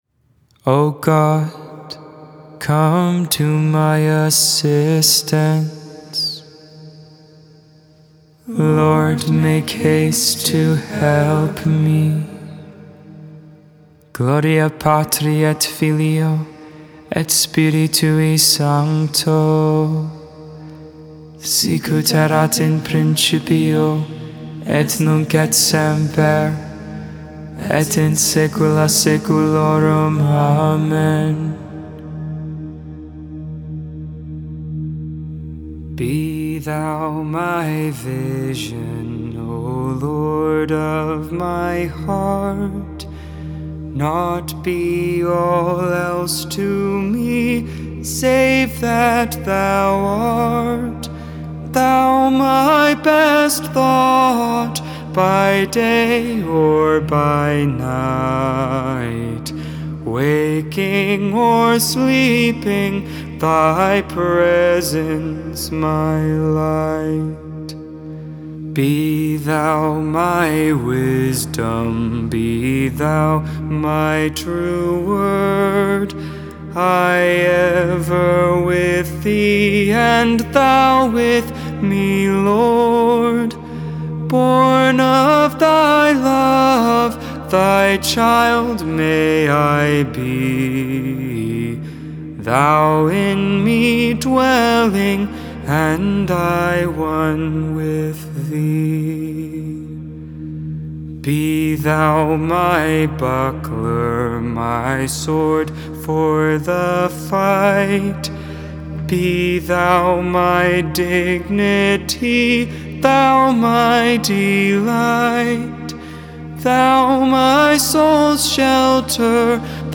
Lauds (Morning Prayer) for the Thursday of the 2nd week in Ordinary Time, March 17th, 2022.